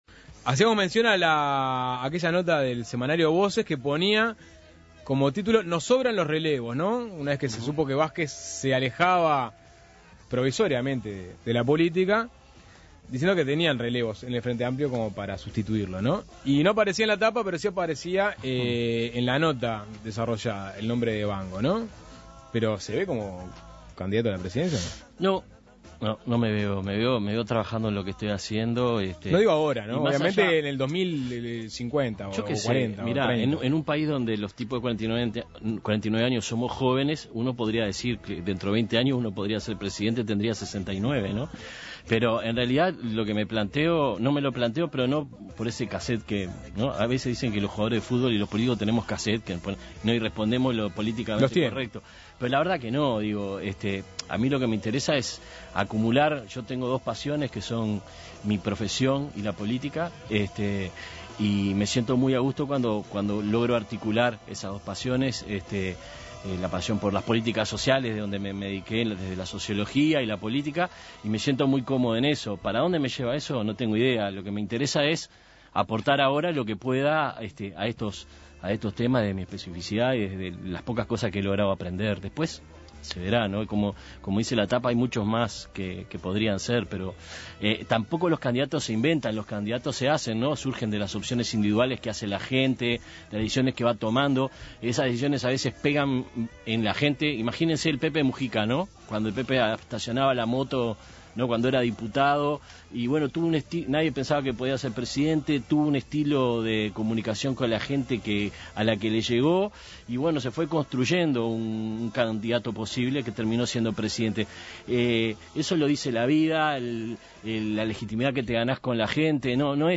En la actual legislatura, ha sido uno de los diputados que ha salido con más fuerza a confrontar con la propuesta de Vamos Uruguay de bajar la edad de imputabilidad penal de 18 a 16 años. Suena Tremendo recibió a Julio Bango